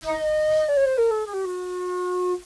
flute2.wav